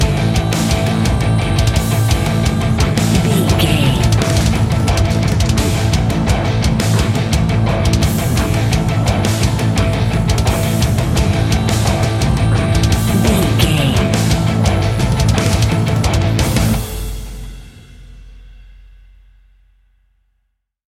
Epic / Action
Fast paced
Aeolian/Minor
heavy metal
Heavy Metal Guitars
Metal Drums
Heavy Bass Guitars